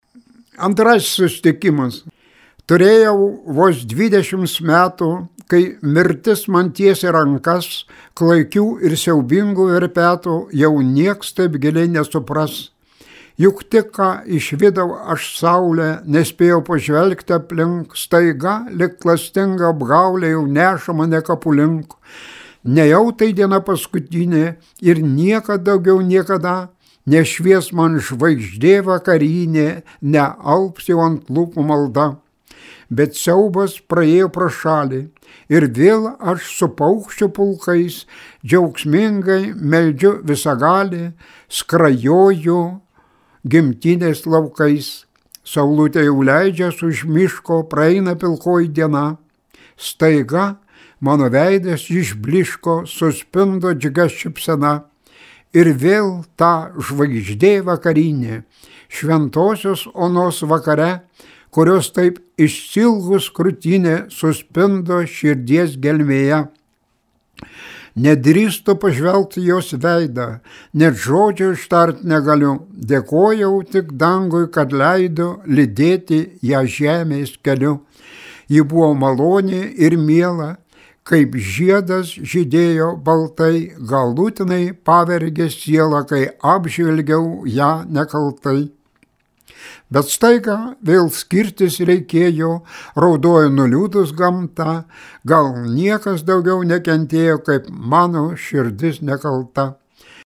Kviečiame paklausyti legendinio partizano Jono Kadžionio-Bėdos eilių, jo paties skaitomų: